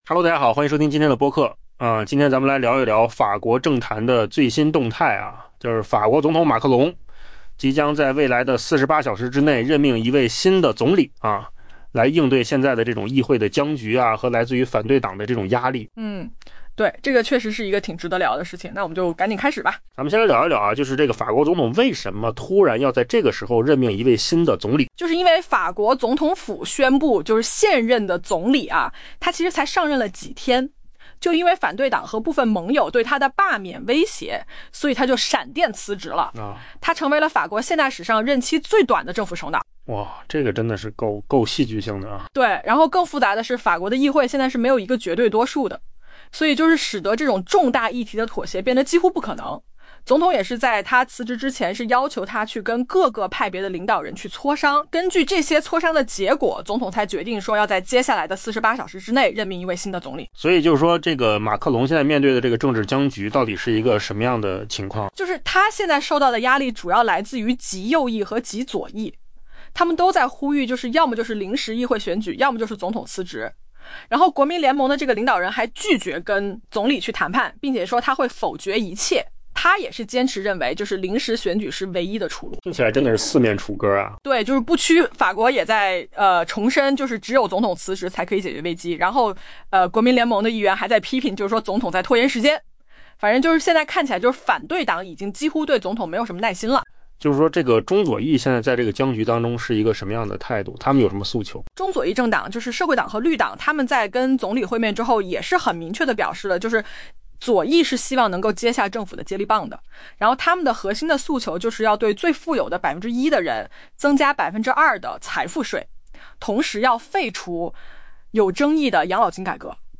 AI 播客：换个方式听新闻 下载 mp3 音频由扣子空间生成 法国总统府于当地时间周三表示，总统埃马纽埃尔·马克龙 （Emmanuel Macron） 将在未来 48 小时内任命新总理。